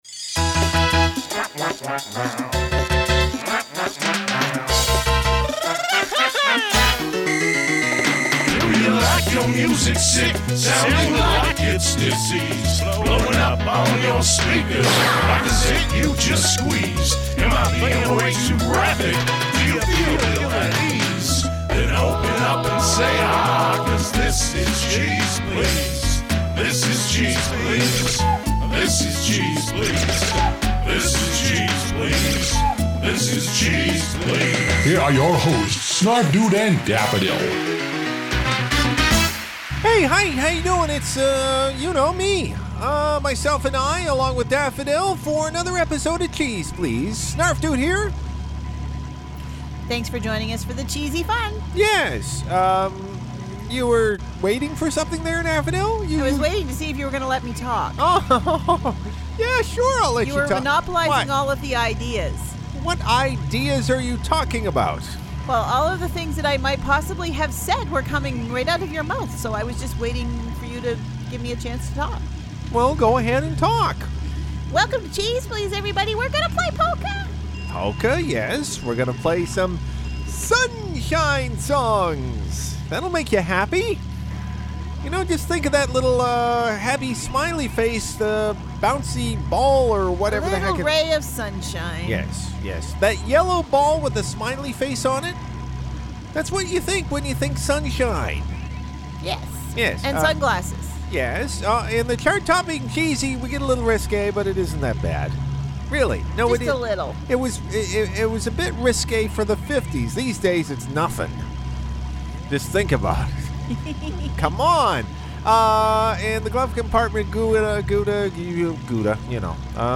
Keeping it positive with Polka and Sunshine songs.....